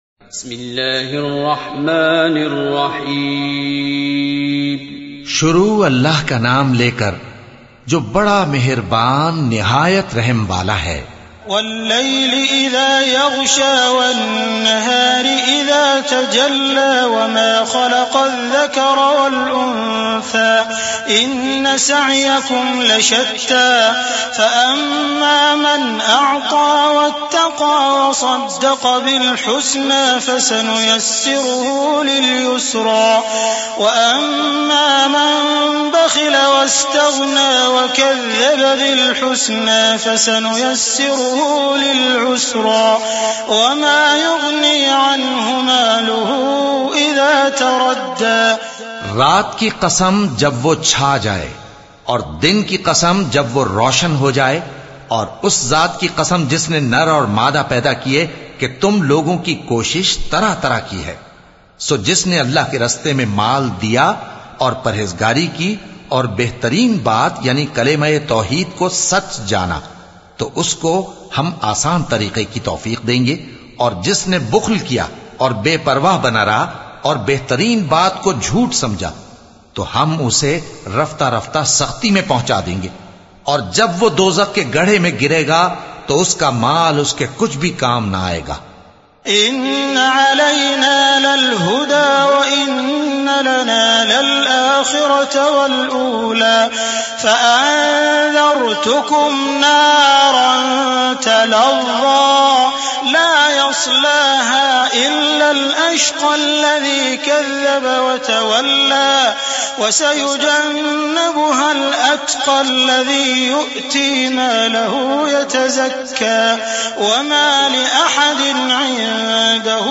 Surah-Al-Layl-with-Urdu-translation-092-The-night.mp3